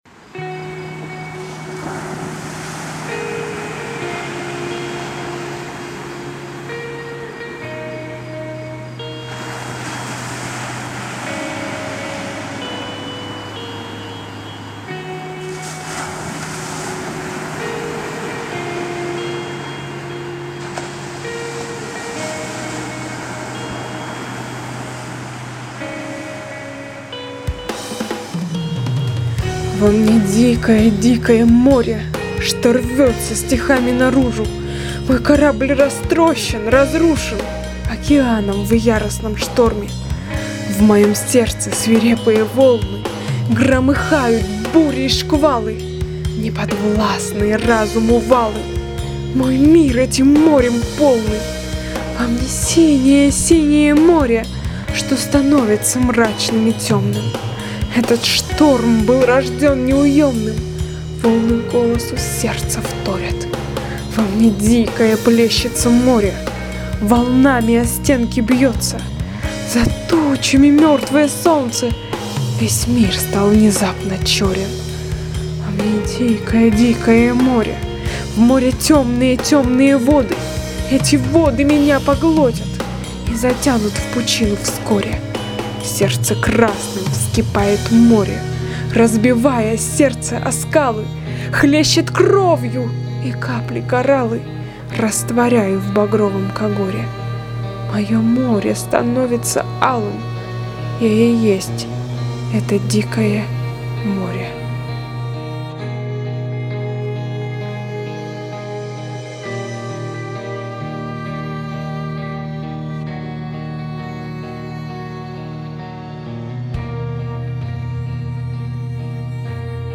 ты притягиваешь своим сладким голосом и заставляешь нырять в свой (твой) мир!!